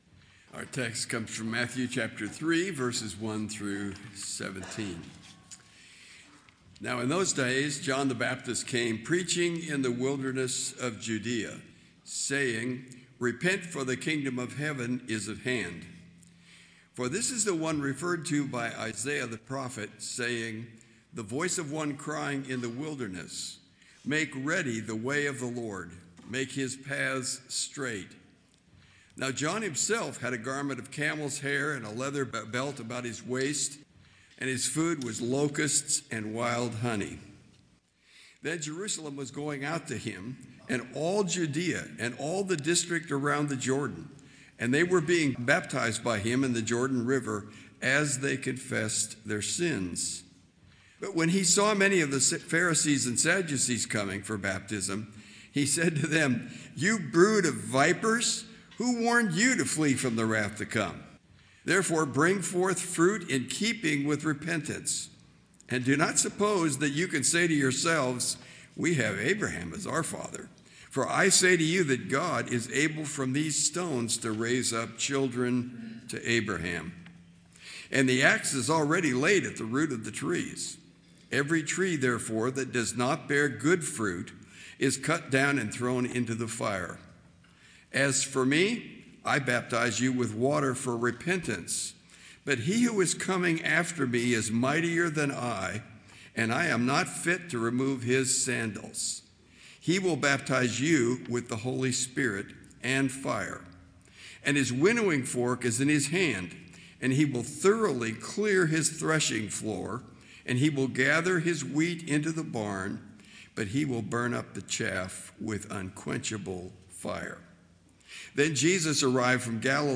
Truth Or Consequences (1 Kings 13:1-34) Sermons - Community Bible Chapel, Richardson, Texas podcast